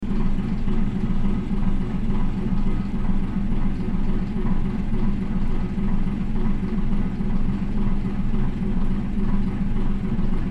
Sound Effects
Loud Bus Engine